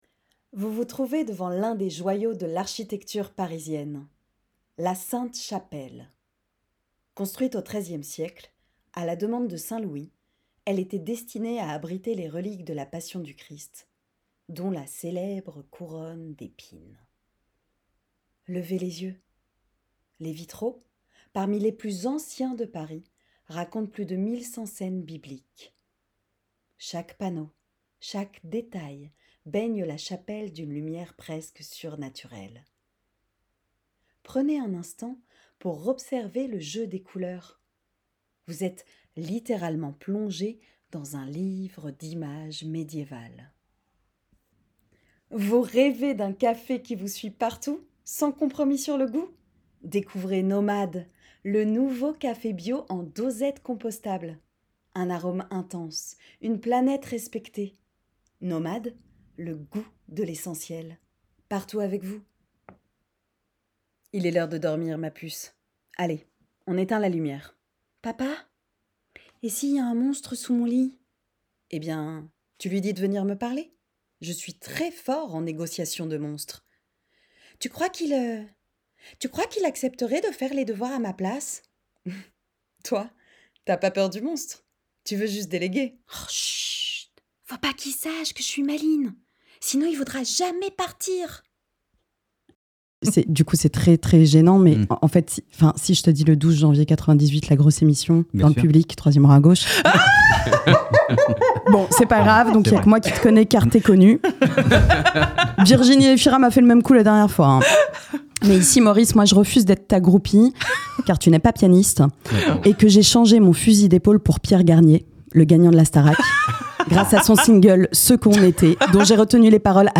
Voix off
- Basse